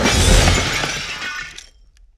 crash1.wav